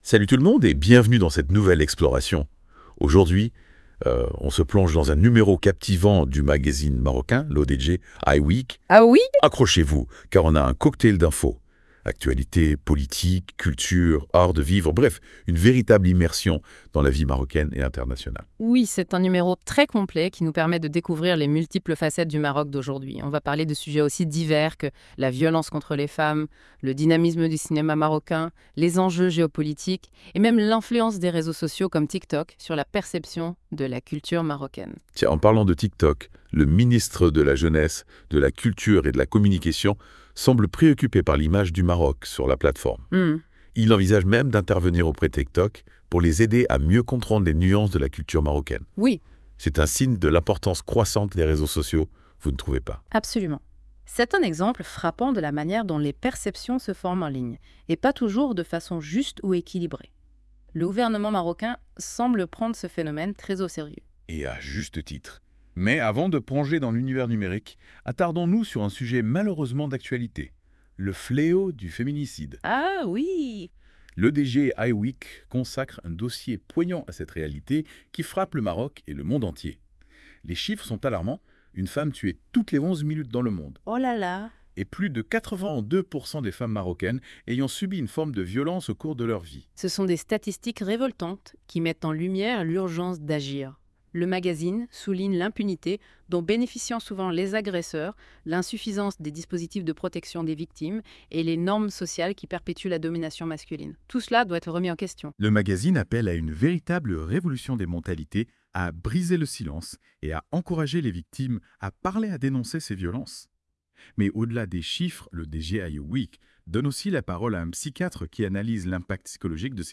Podcast débat Iweek 64.wav (68.88 Mo)